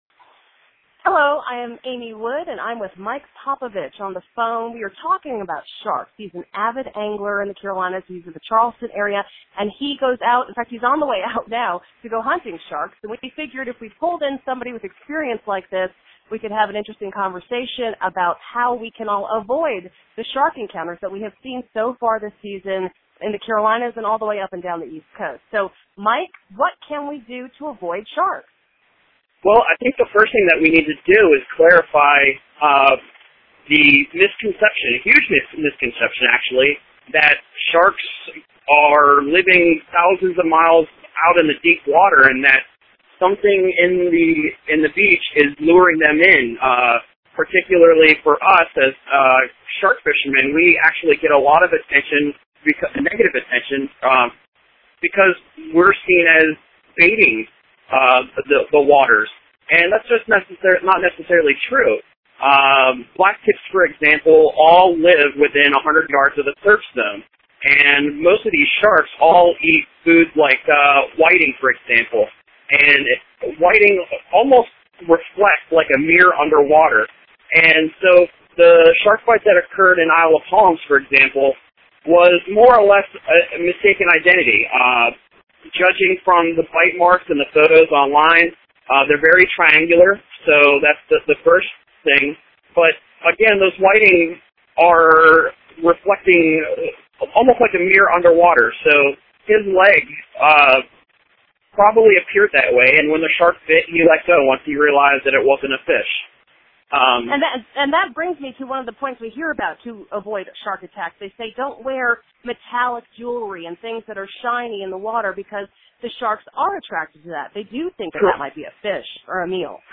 My interview